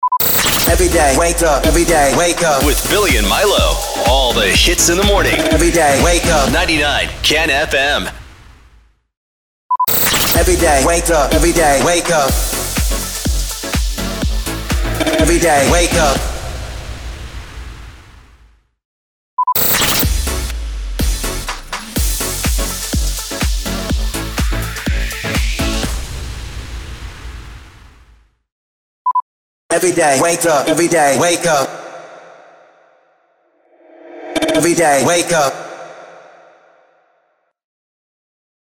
483 – SWEEPER – MORNING SHOW
483-SWEEPER-MORNING-SHOW.mp3